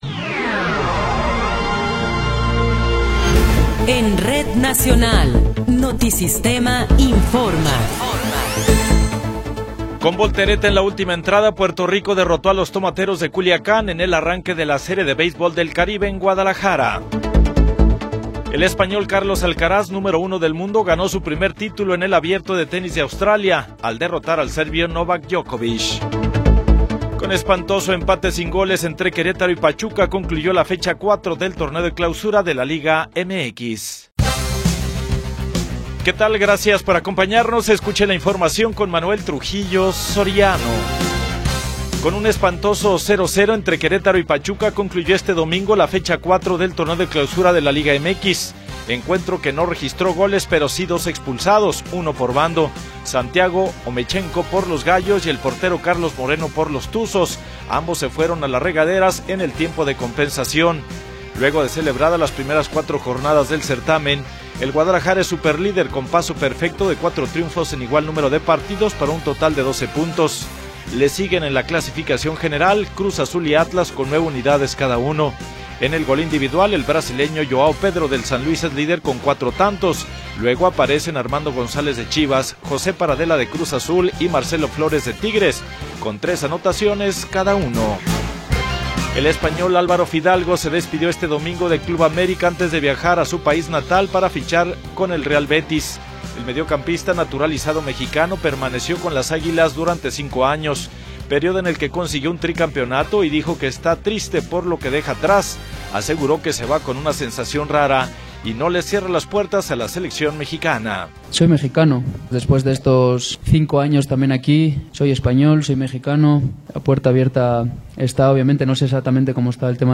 Noticiero 20 hrs. – 1 de Febrero de 2026